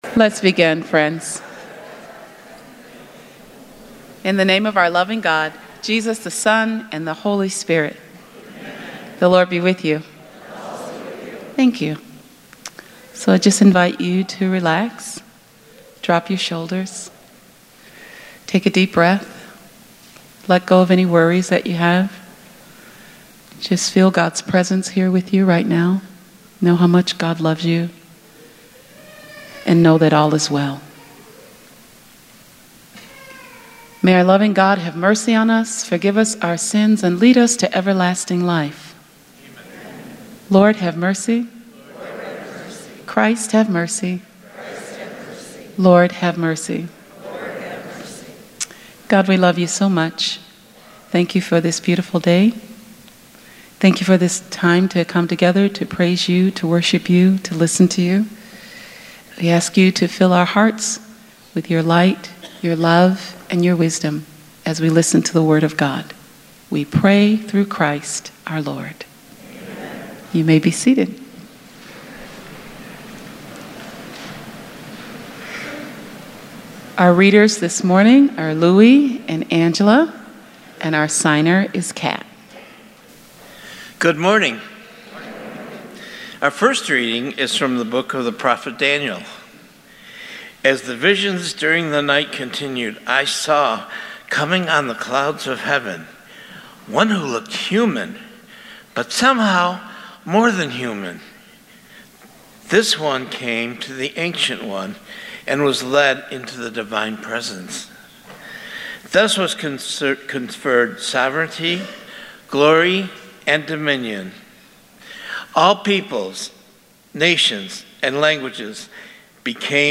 Spiritus Christi Mass November 25th, 2018